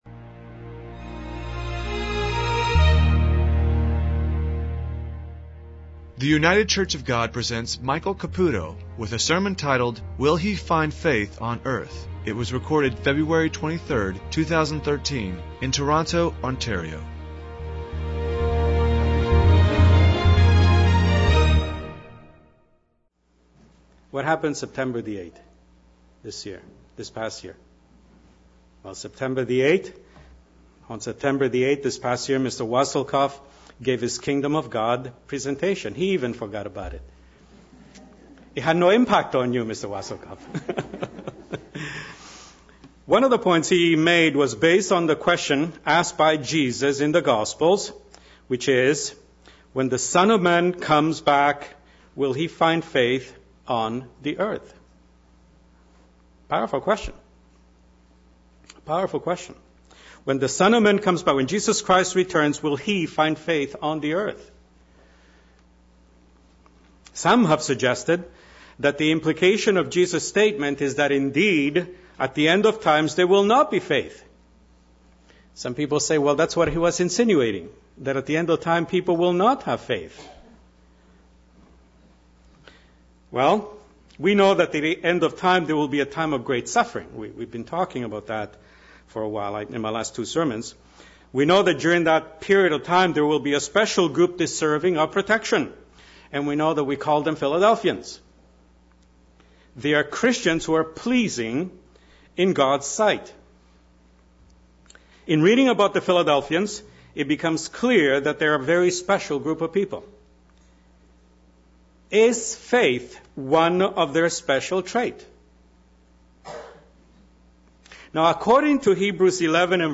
Given in Toronto